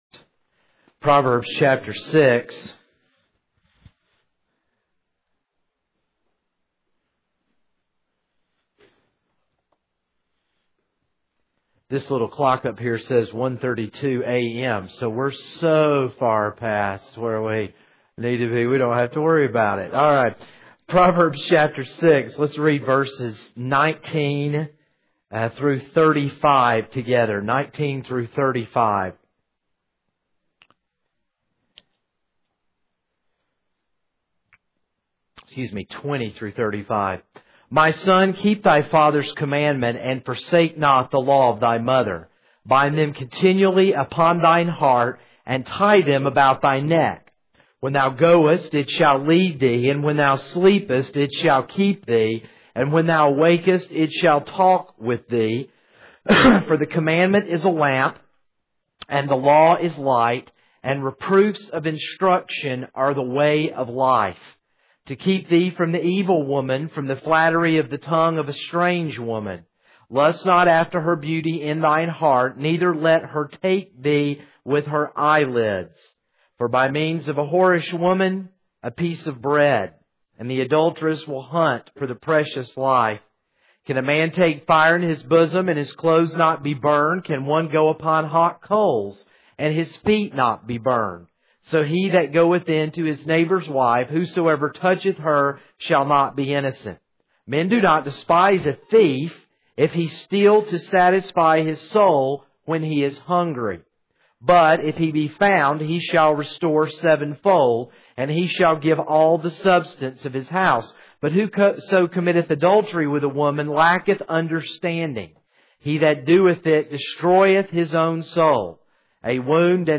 This is a sermon on Proverbs 6:20-35.